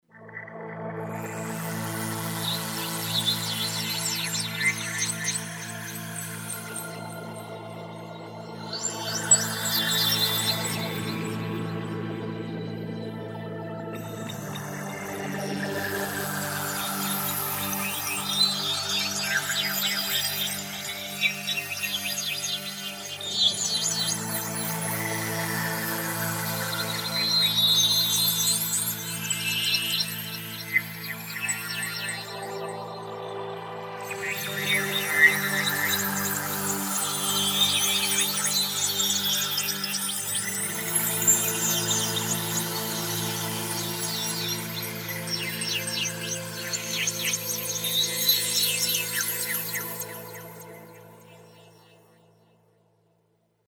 Class: Synth module
sweeping again